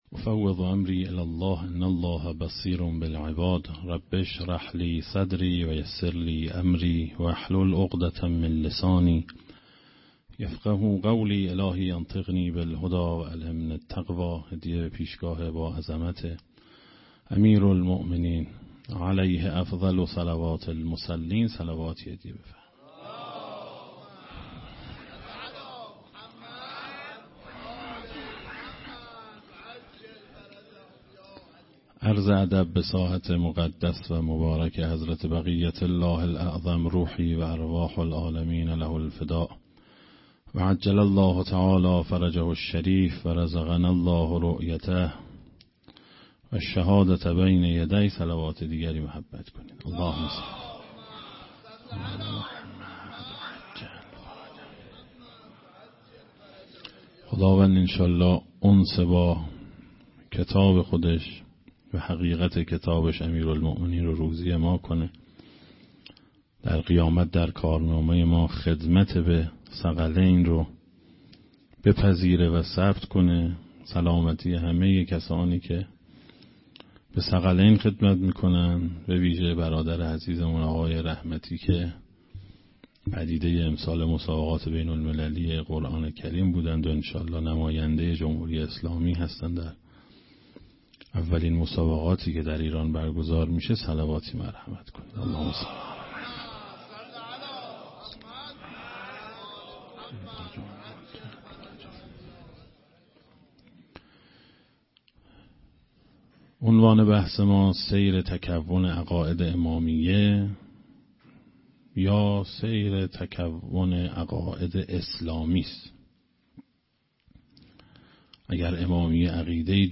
در مجموعه فرهنگی شهدای انقلاب اسلامی
سخنرانی